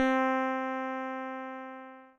◆Guitar
MT-40_60_C-4_Guitar.wav